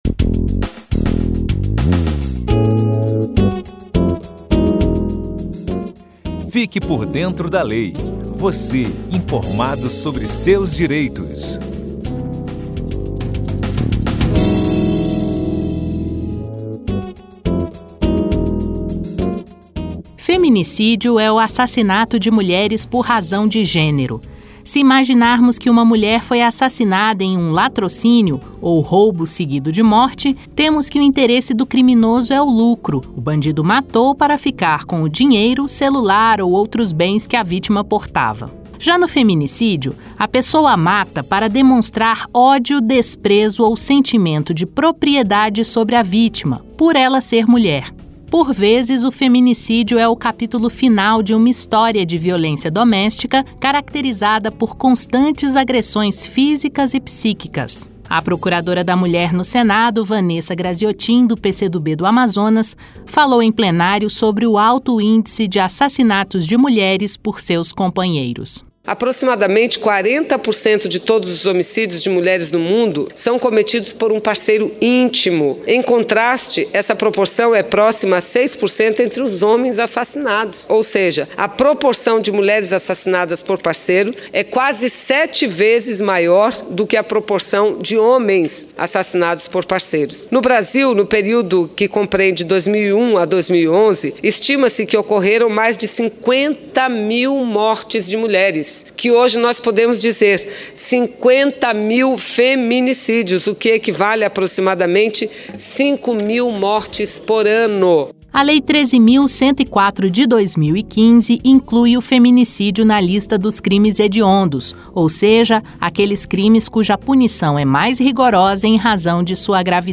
Entrevista com a senadora Vanessa Grazziotin (PCdoB-AM).